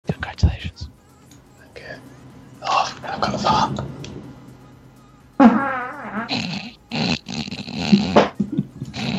Play, download and share Fart v3 original sound button!!!!
fart-v3.mp3